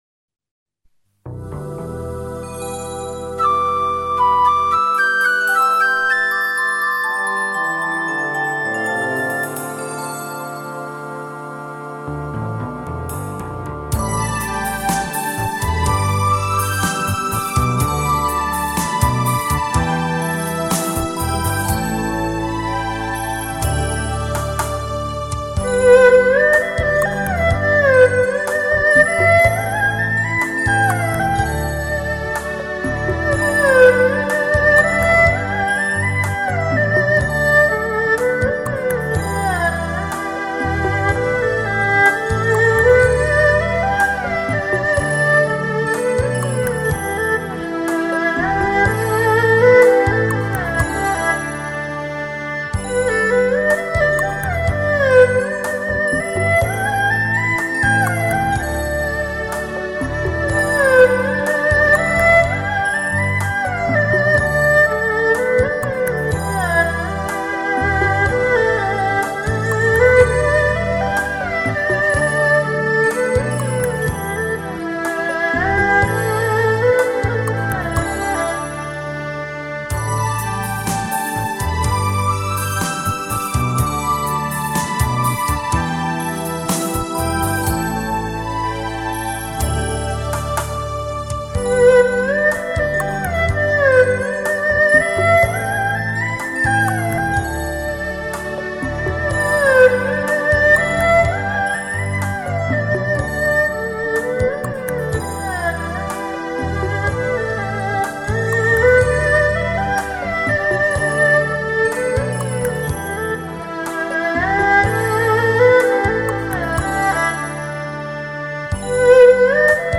无法不爱的二胡深情演绎